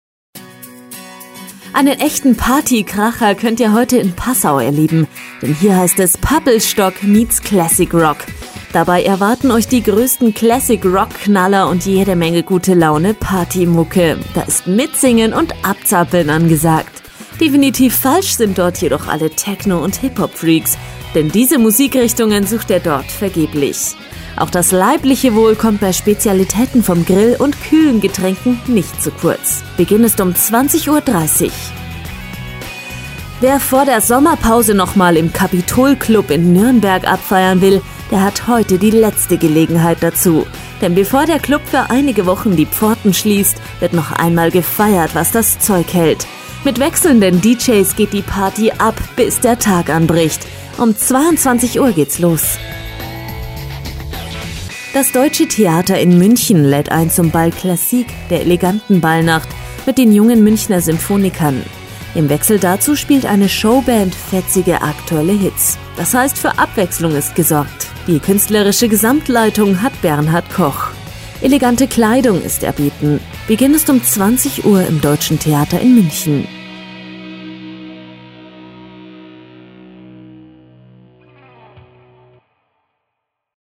Sprechprobe: Sonstiges (Muttersprache):
female voice over artist german